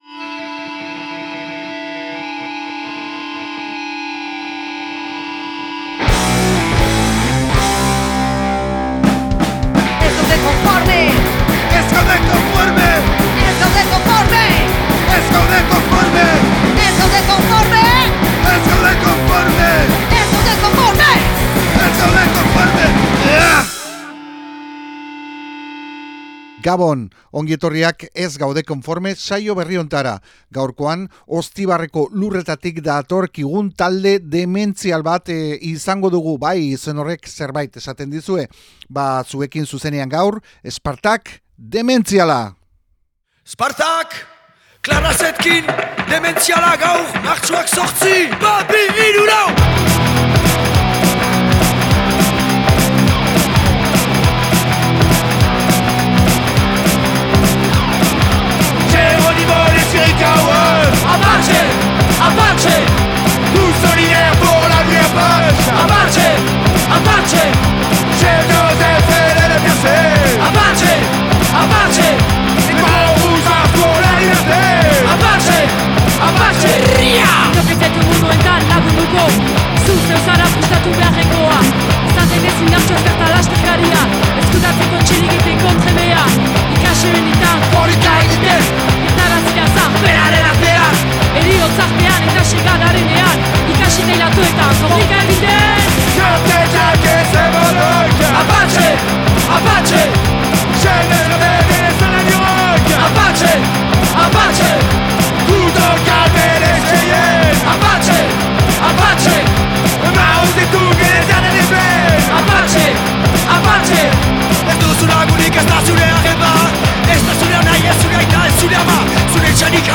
hip hop, punk
Elkarrizketa